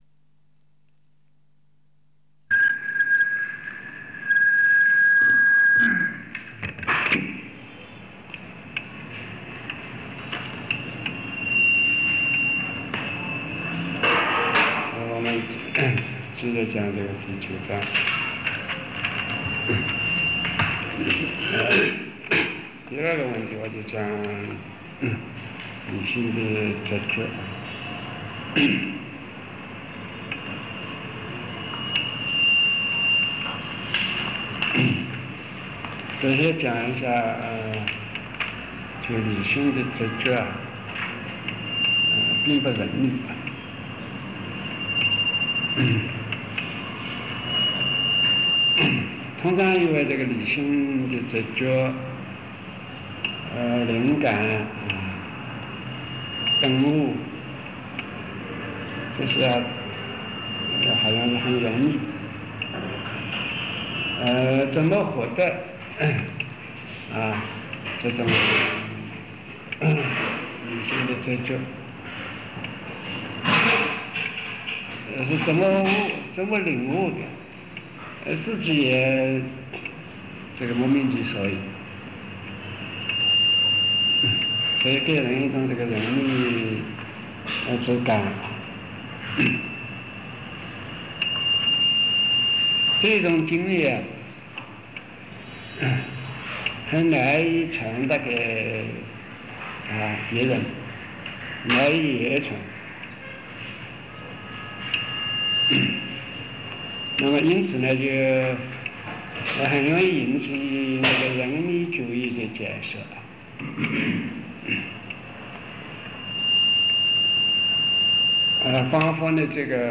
冯契授课录音 第九章第三盘ab面